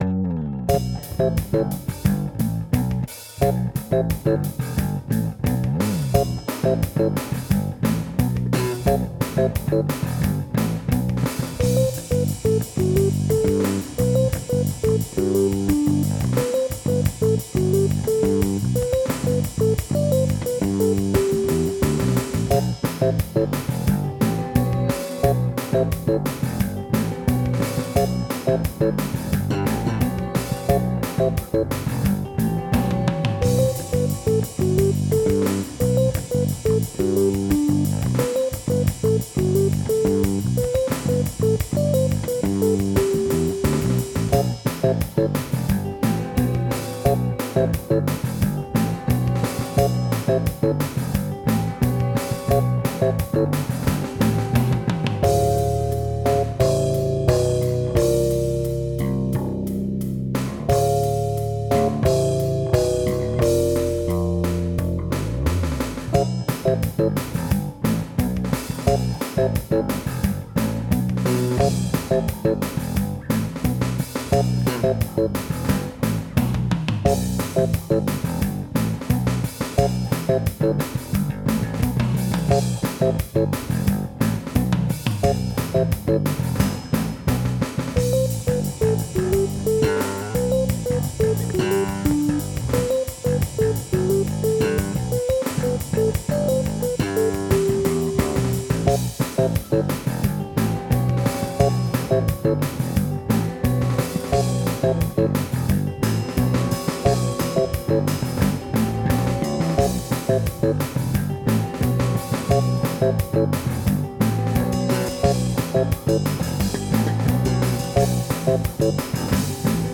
with no guitar parts at all